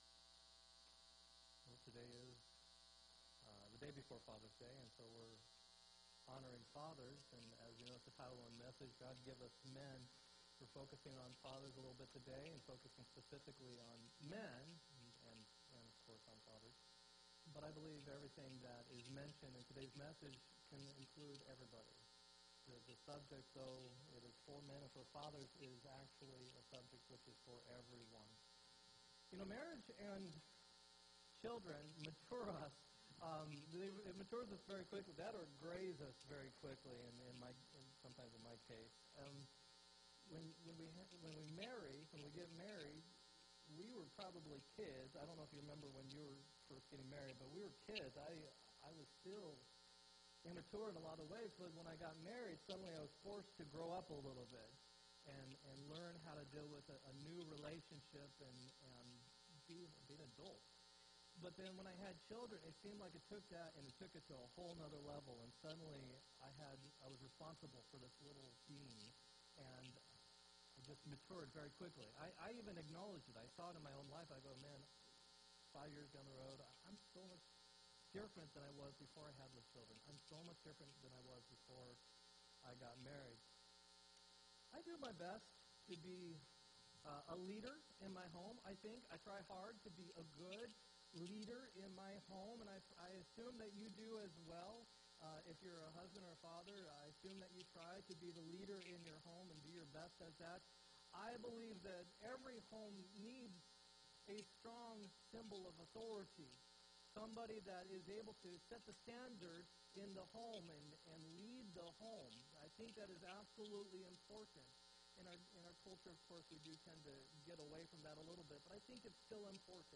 6-17-17 sermon